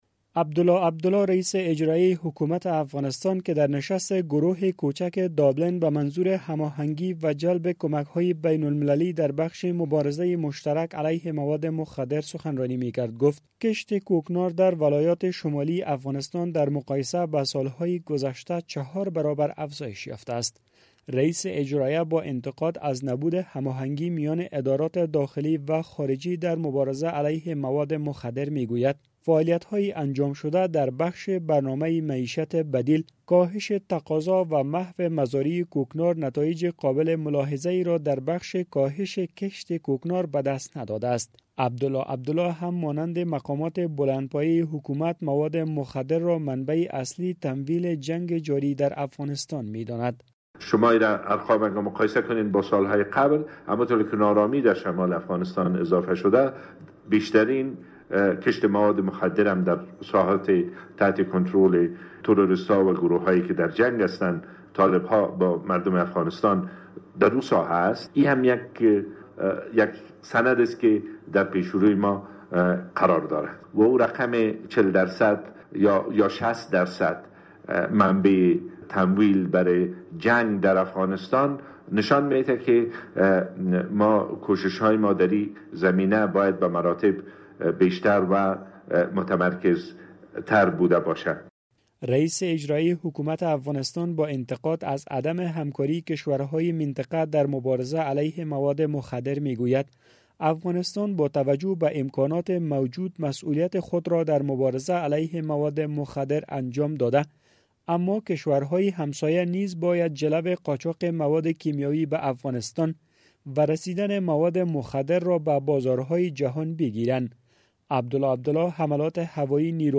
عبدالله عبدالله رئیس اجرائیه افغانستان